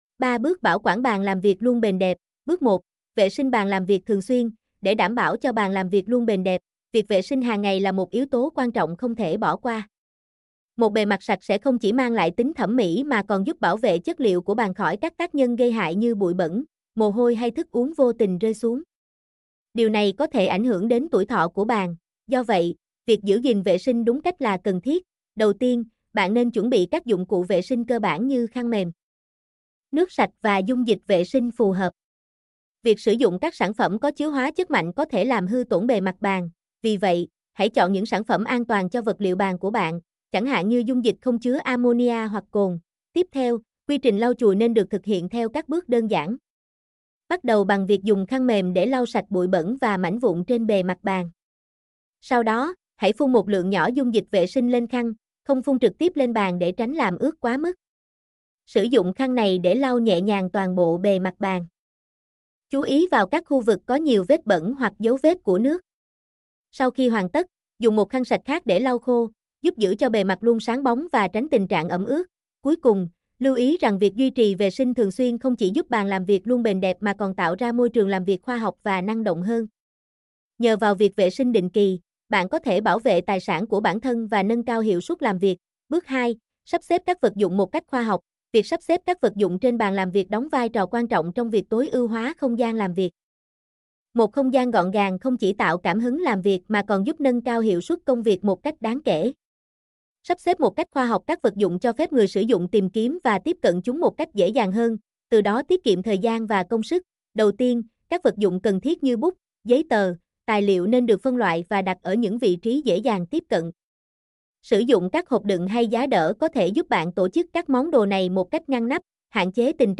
mp3-output-ttsfreedotcom-19.mp3